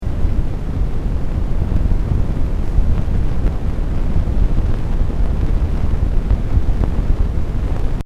A noise problem with Tascam uh 7000 + rode nt-1 + mac os.x Yosemite 10.10.3 + logic
Anyway I have recorded a sample of the noise I have been talking about.